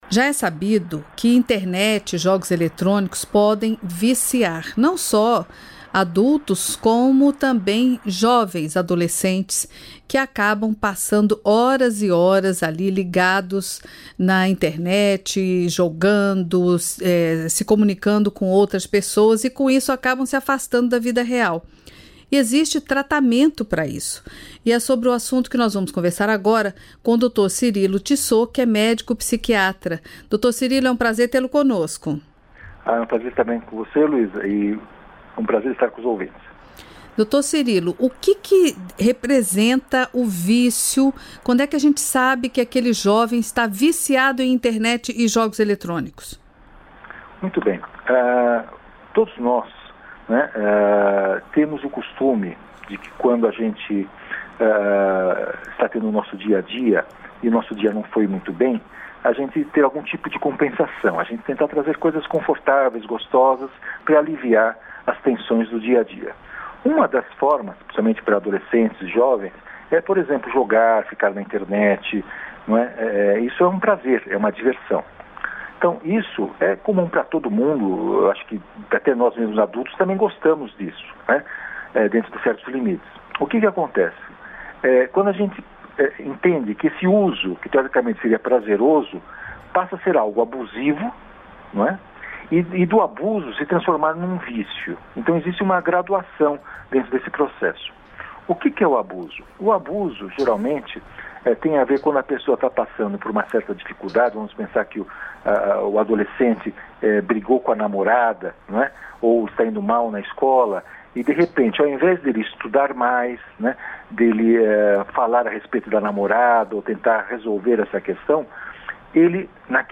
O especialista afirma que os pais precisam ficar em alerta e observar os hábitos e mudanças de comportamento dos filhos ainda na infância. Ouça a entrevista completa Aqui .